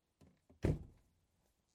Closing a car door from a distance